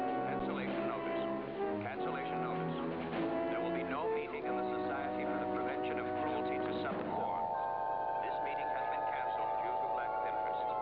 There are constant overhead announcements in Chimera throughout the chapters of The Secret Empire.
cancellation-notice.wav